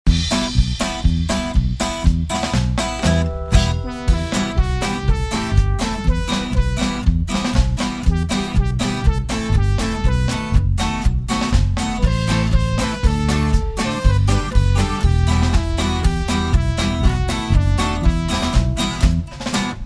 Praise Song for Children